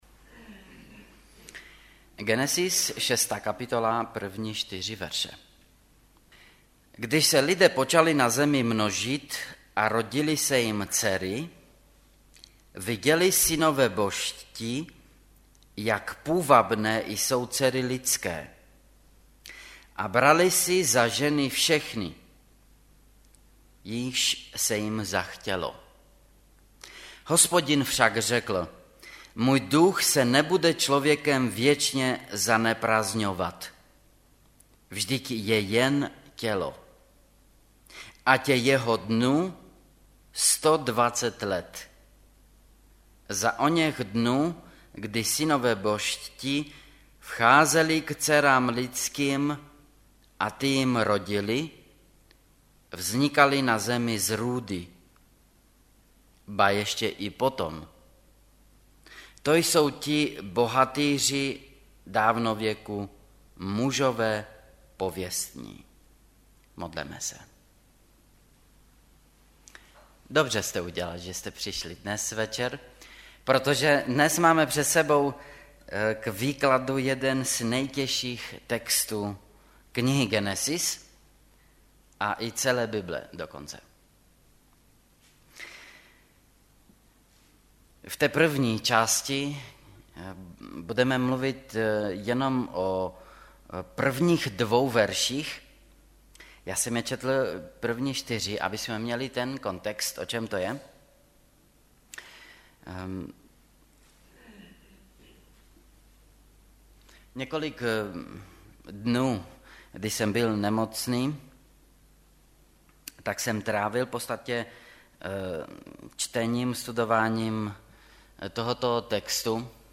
- Genesis 6 Kategorie: Kázání MP3 Zobrazení: 3348 Kdo byli synové Boží?